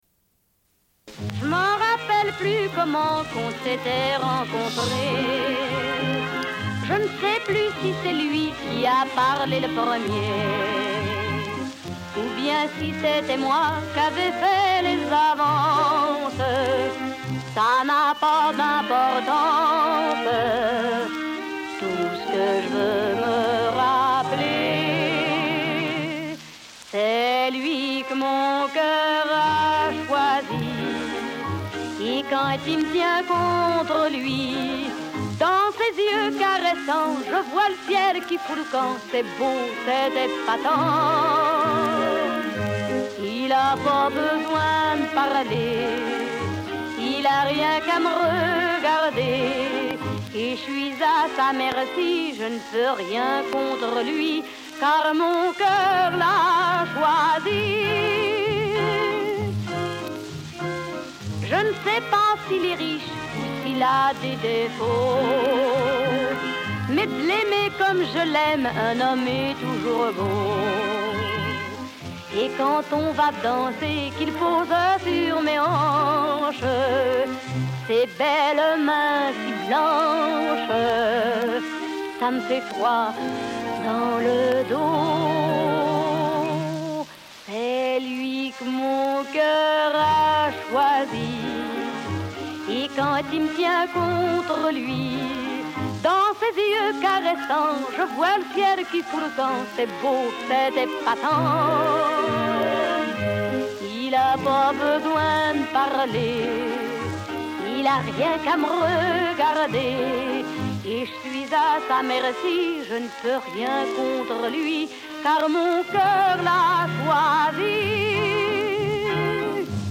Une cassette audio, face A31:20